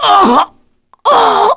Kasel-Vox_Dead_kr.wav